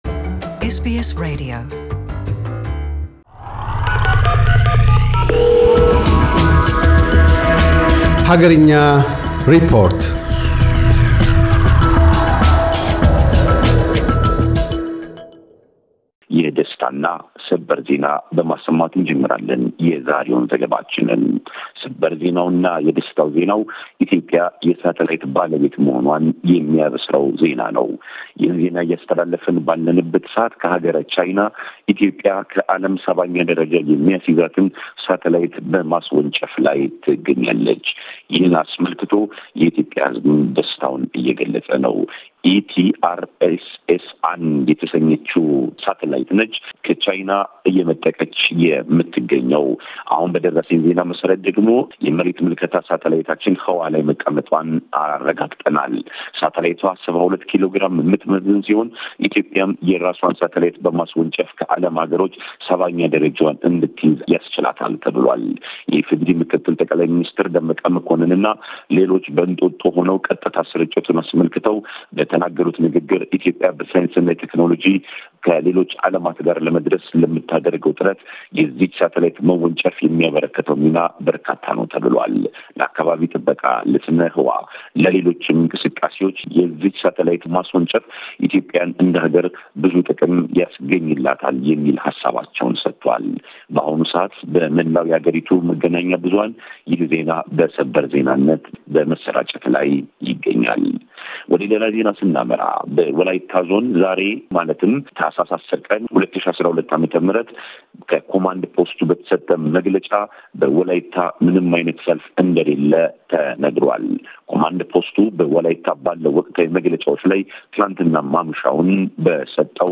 አገርኛ ሪፖርት - ኢትዮጵያ የምድር ምልከታ ሳተላይቷን ከቻይና ማምጠቋን ቀዳሚ ትኩረቱ አድርጓል።